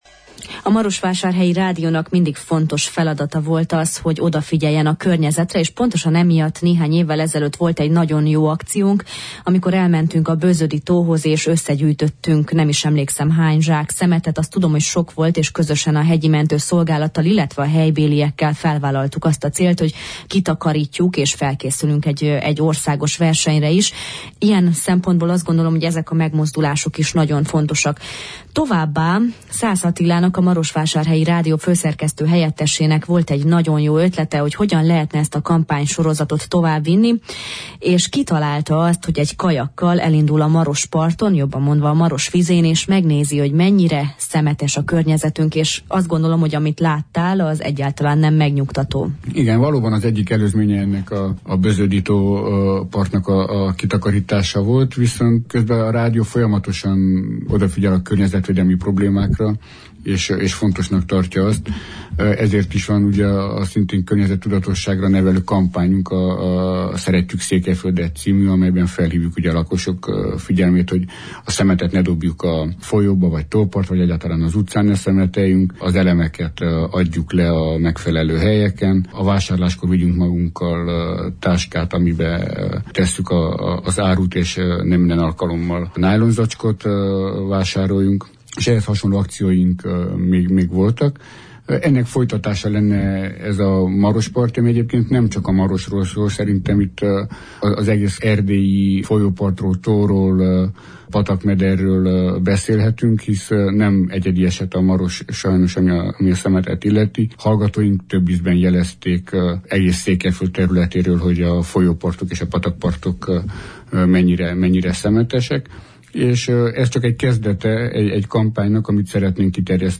beszélgettünk a mai Jó reggelt, Erdélyben!